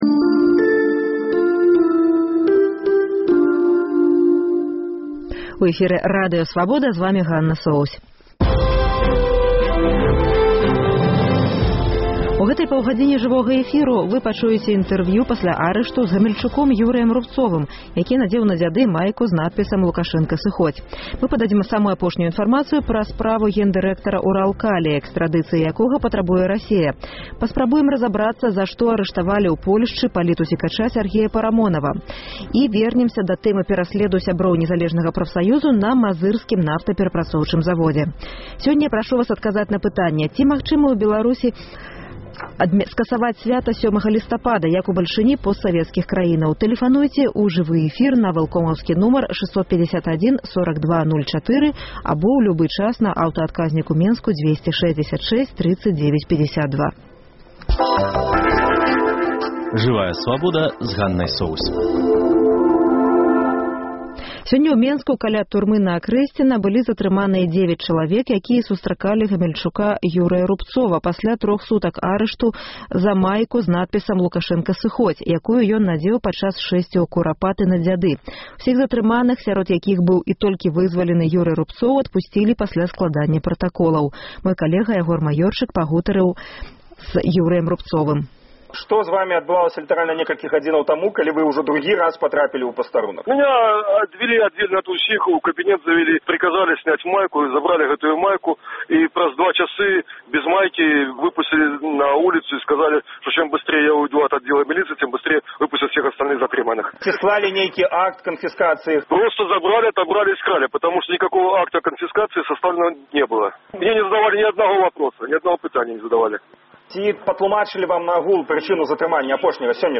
Тэлефануйце ў жывы эфір на вэлкомаўскі нумар 651 42